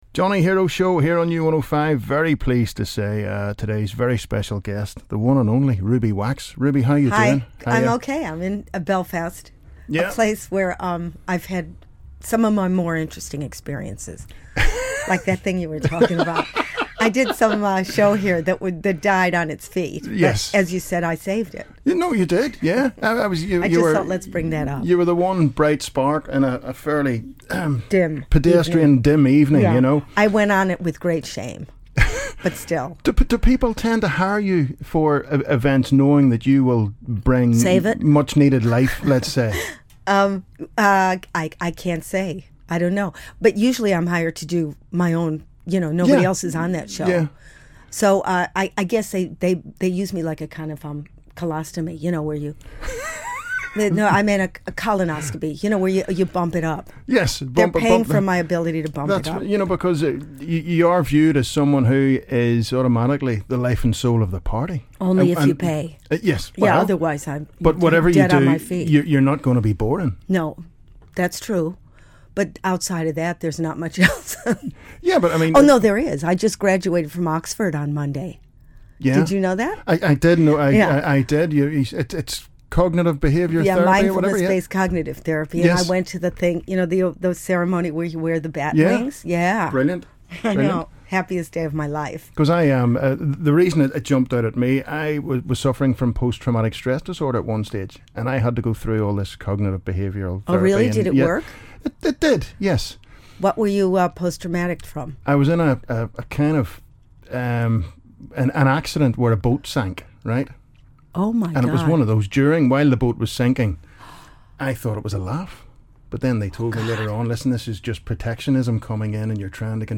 Ruby Wax Interview - Wednesday 25th September - U105 Drive